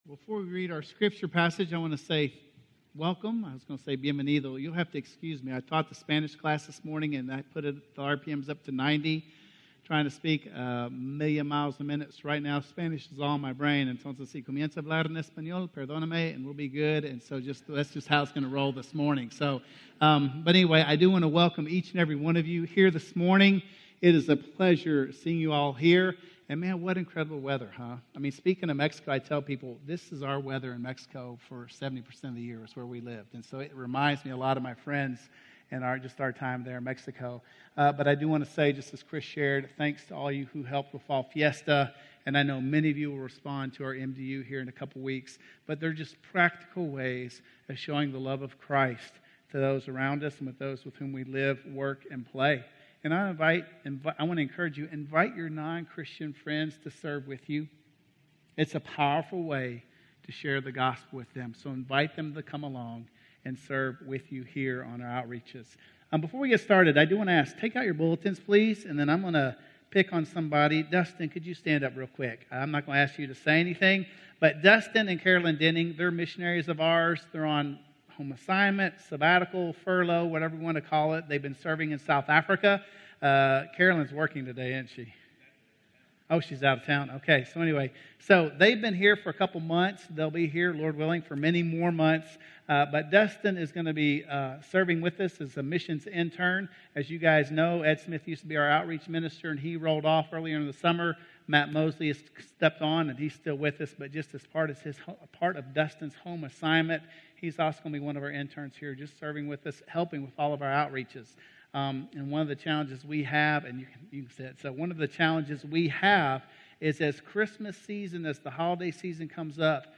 Sermons - Woodbine